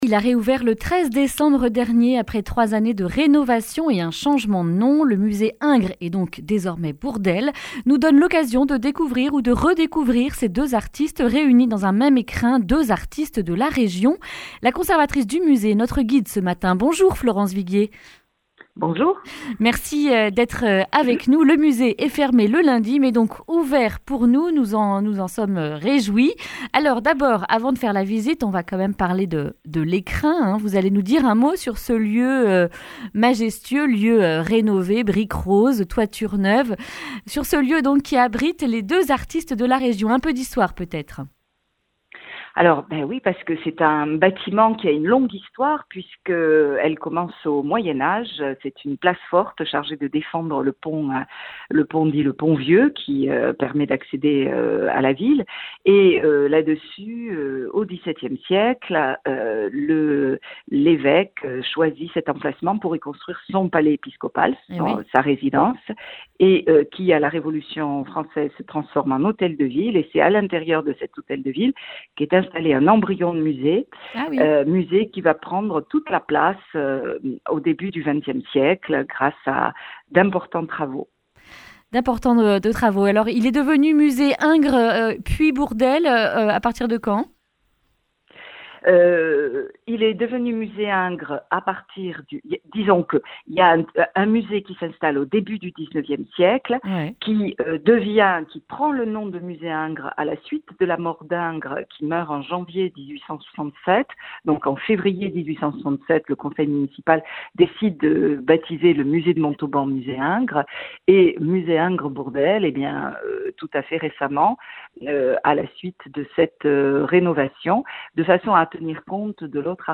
Visite guidée dans le musée Ingres Bourdelle rénové de Montauban